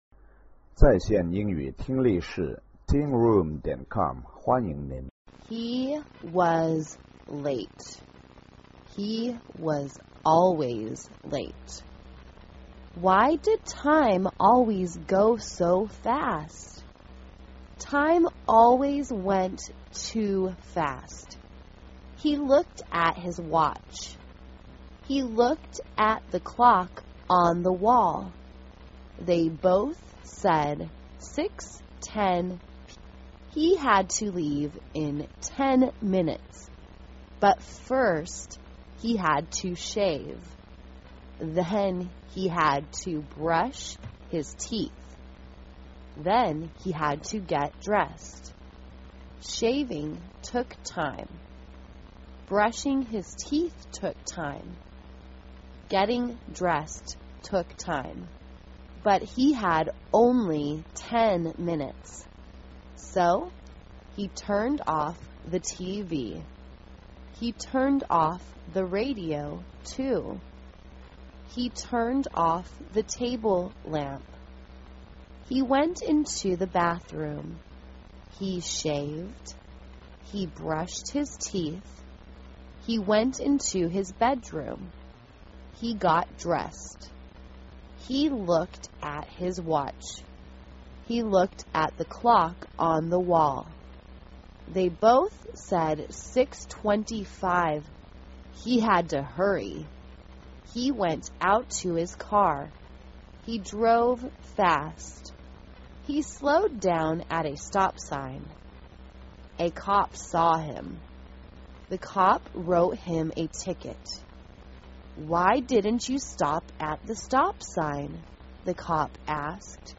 简单慢速英语阅读:Don’t Be Late 听力文件下载—在线英语听力室